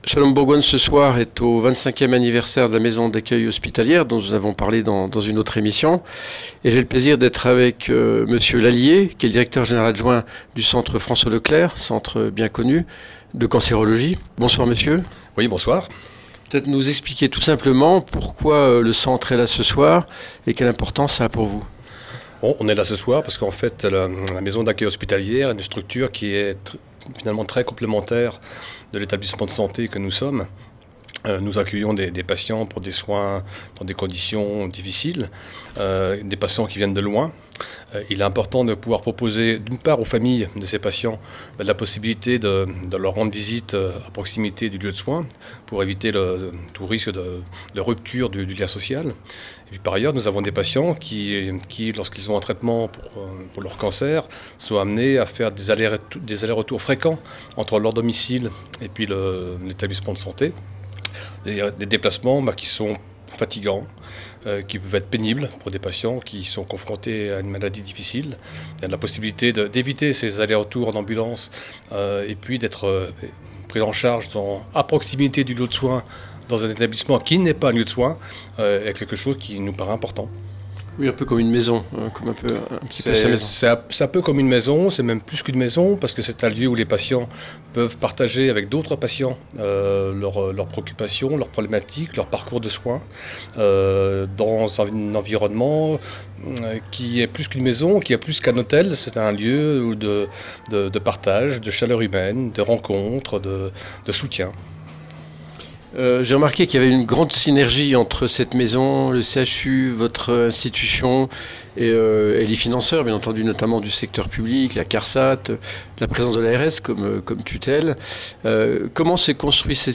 Shalom Bourgogne était présente au 25ieme anniversaire de la Maison d'accueil Hospitalière :